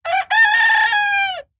aaj_0043_Rooster_02